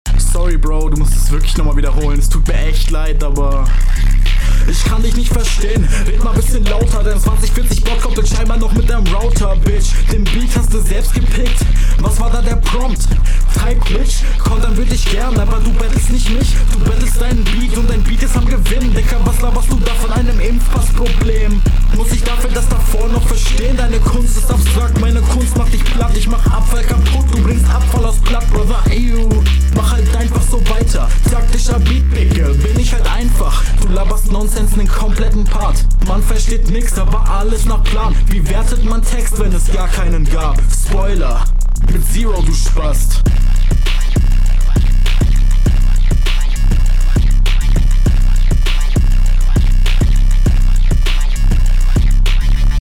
Rappst auch cool. Audioqualität viel besser.
Für den Beat ist das alles eine brauchbare Runde, aber der Beat ist dermaßen schlecht.